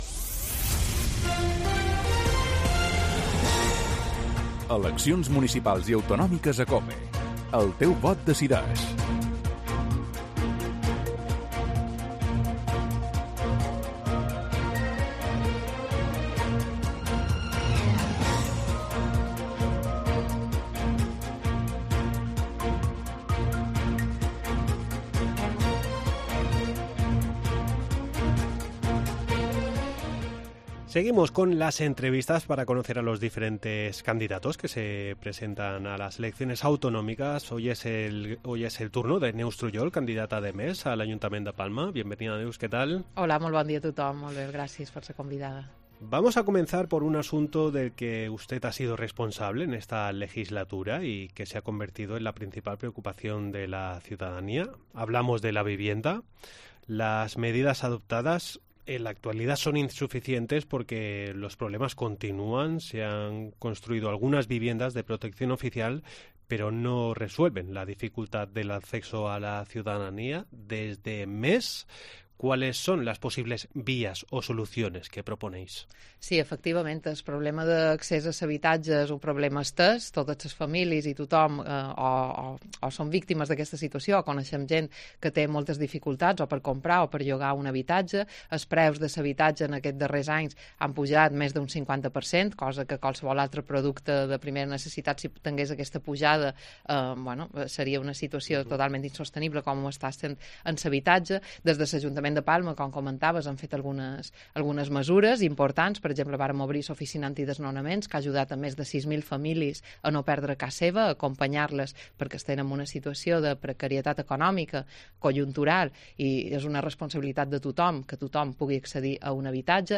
AUDIO: Seguimos con las entrevistas electorales. Hoy es el turno de Neus Truyol, la candidata de MÉS al Ayuntamiento de Palma.